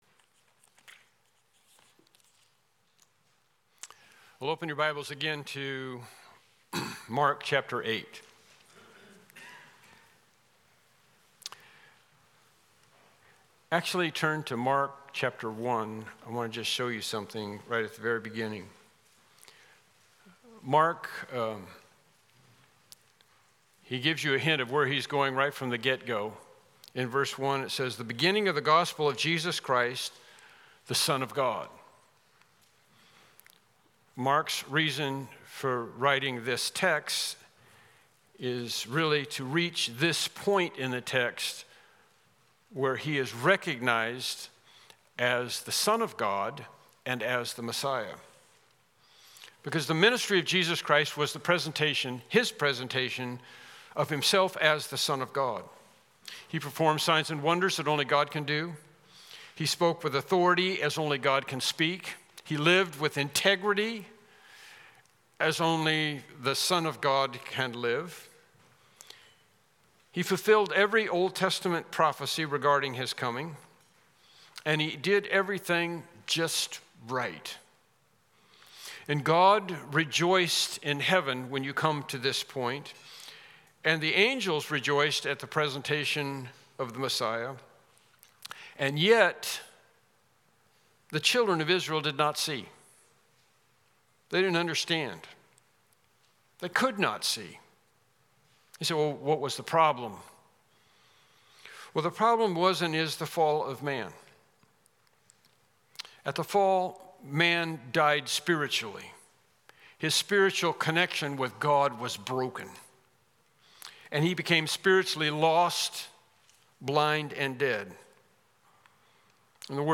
Unknown Service Type: Morning Worship Service « Lesson 7